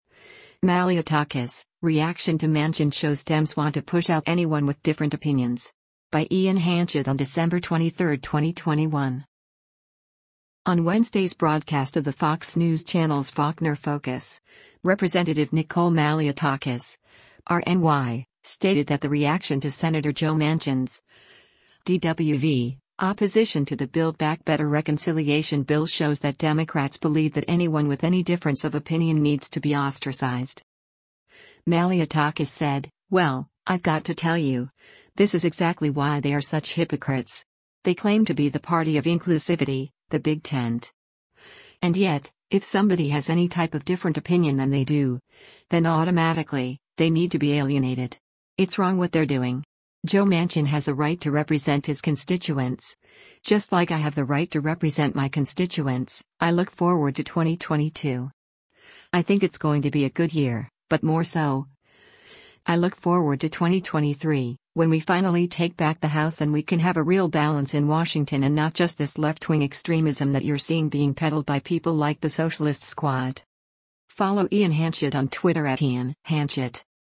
On Wednesday’s broadcast of the Fox News Channel’s “Faulkner Focus,” Rep. Nicole Malliotakis (R-NY) stated that the reaction to Sen. Joe Manchin’s (D-WV) opposition to the Build Back Better reconciliation bill shows that Democrats believe that anyone with any difference of opinion needs to be ostracized.